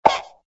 firehydrant_popup.ogg